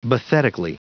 Prononciation du mot : bathetically
bathetically.wav